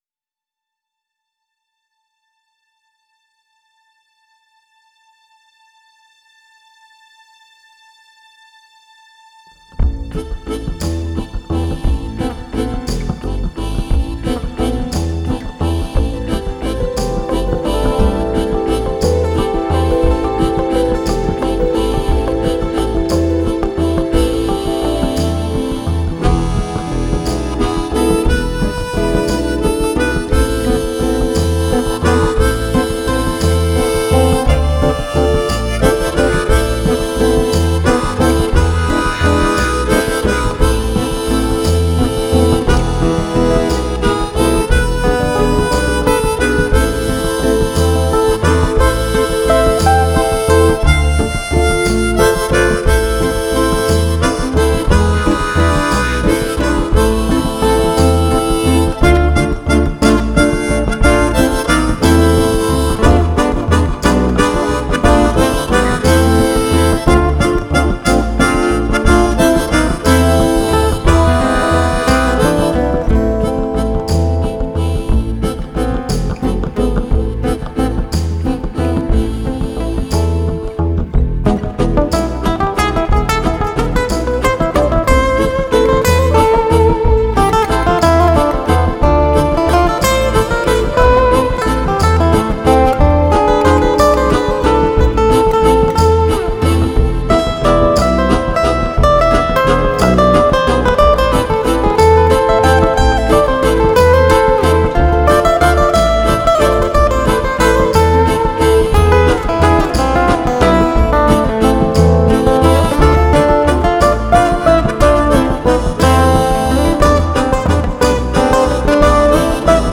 live instrumental album
GK Guitar
Harmonicas
Flute
Percussion